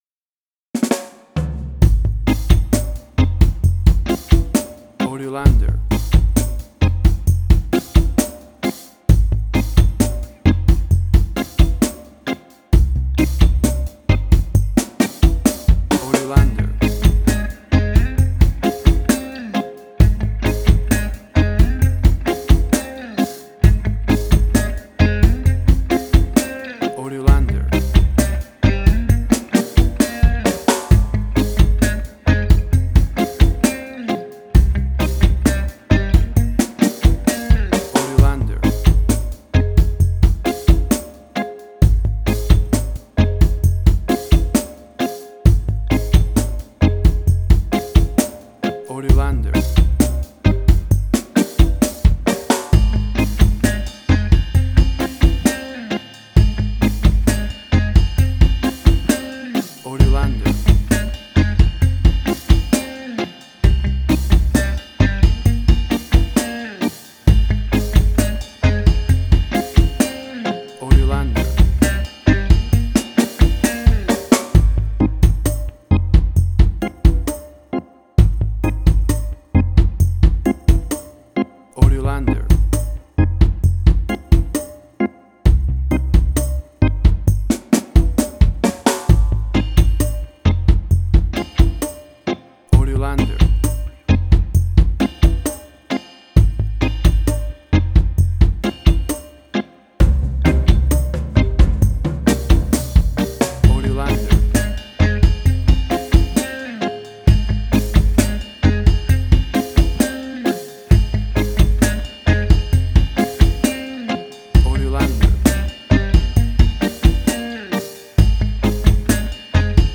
Reggae caribbean Dub Roots
Tempo (BPM): 66